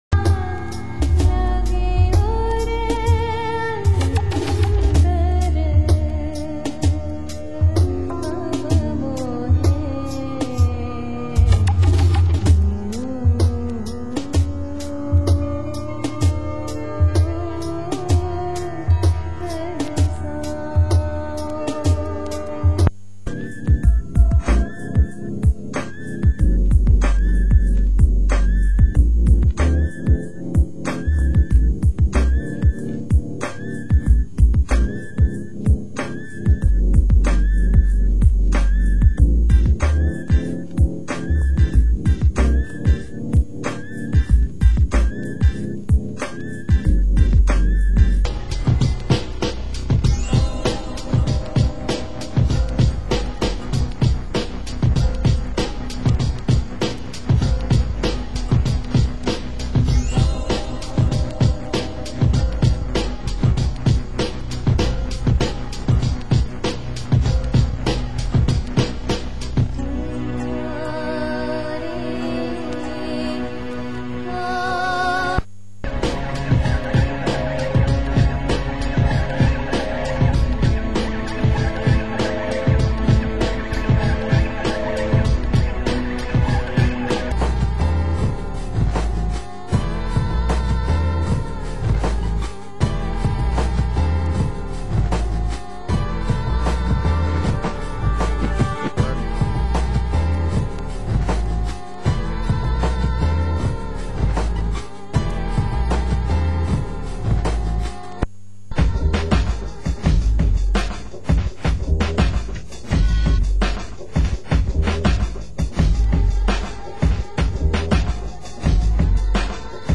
Genre: Trip Hop